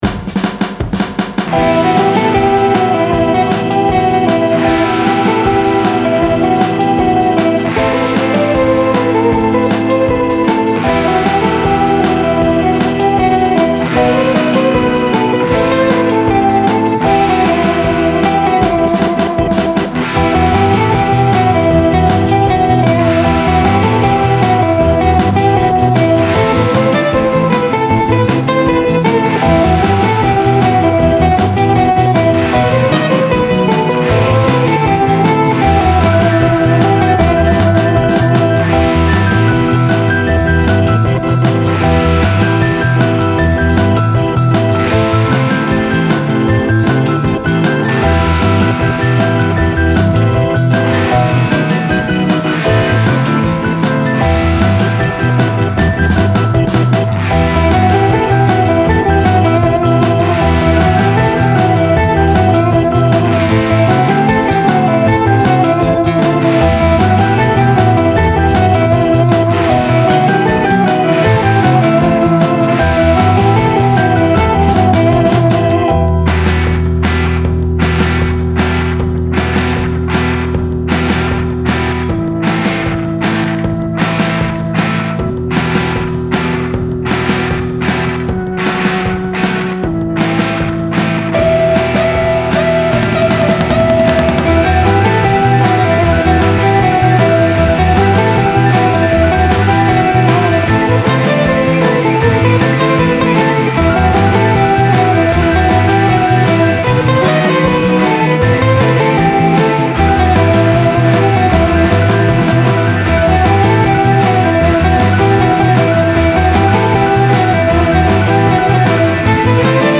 Style Style Rock
Mood Mood Cool, Driving
Featured Featured Bass, Drums, Electric Guitar +2 more
BPM BPM 155